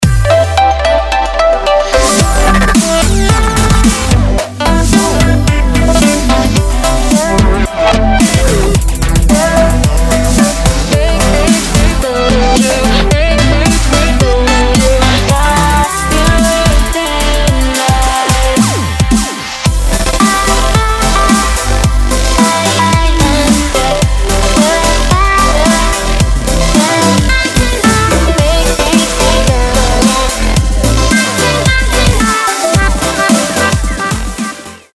Electronic
Dubstep
Очень светлая и добрая электроника